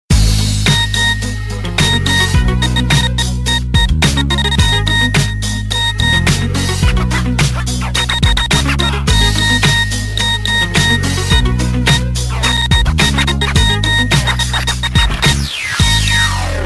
message tone ring tone message alert message ring